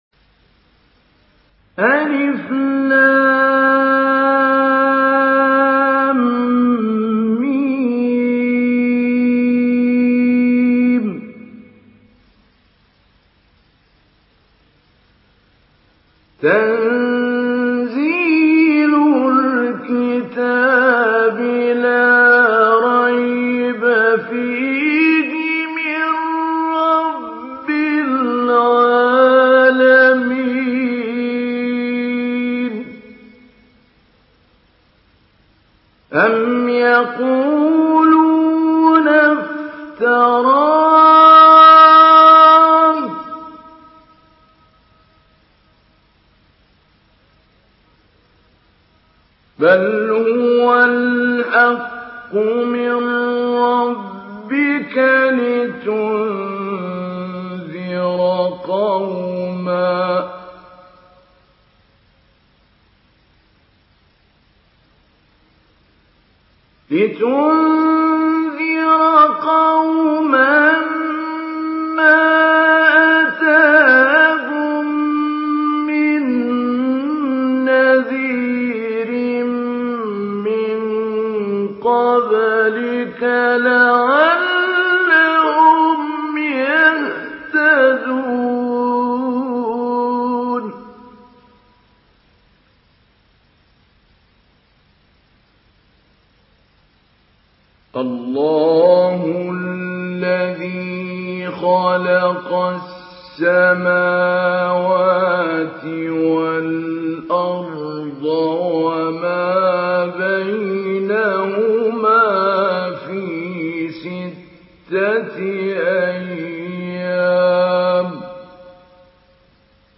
Surah As-Sajdah MP3 in the Voice of Mahmoud Ali Albanna Mujawwad in Hafs Narration
Surah As-Sajdah MP3 by Mahmoud Ali Albanna Mujawwad in Hafs An Asim narration.